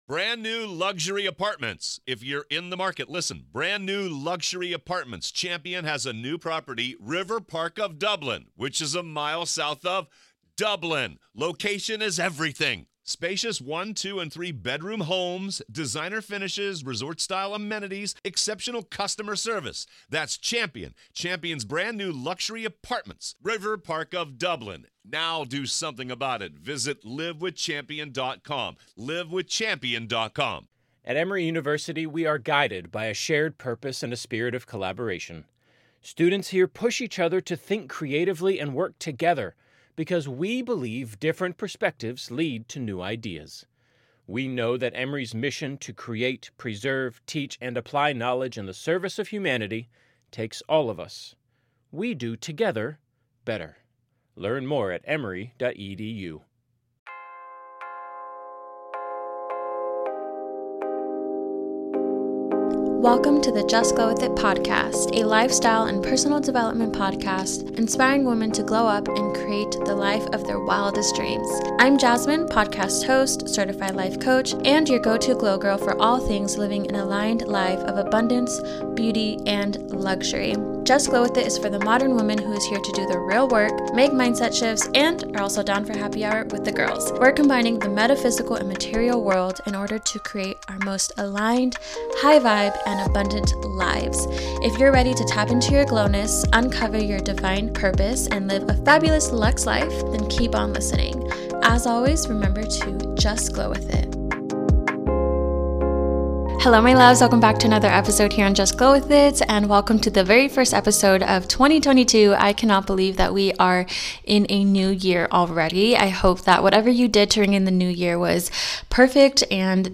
Hi loves! In this episode of Just Glow With It, we're having cozy, chill pep talk to get us inspired for the new year! I'm sharing some self-reflection thoughts along with energetic shifts, mindset shifts and habits I'm doing to make 2022 the best year yet!